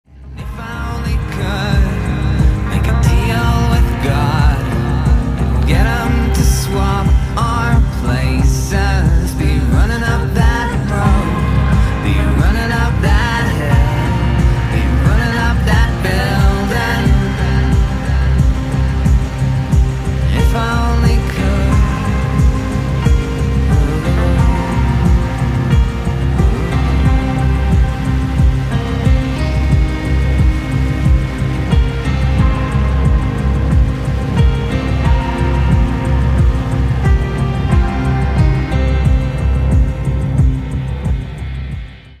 • Качество: 192, Stereo
мужской вокал
атмосферные
спокойные
Cover
Alternative Rock